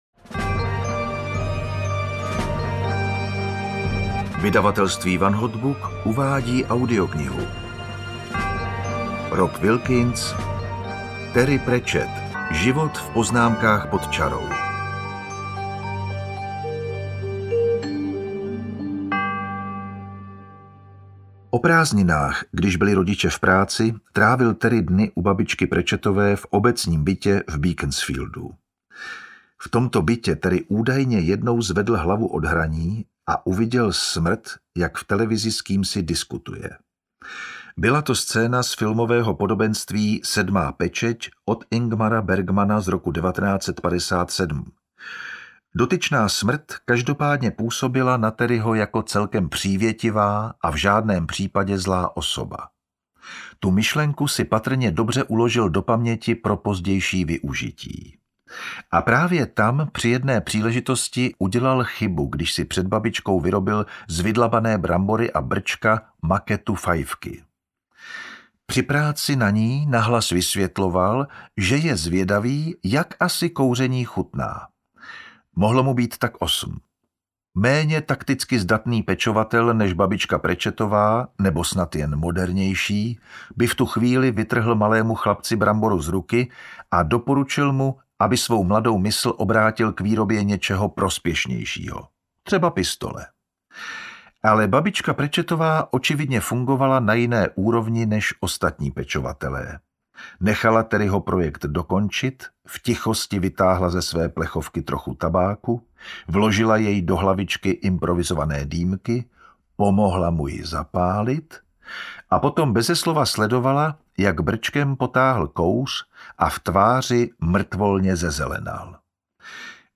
Terry Pratchett: Život v poznámkách pod čarou audiokniha
Ukázka z knihy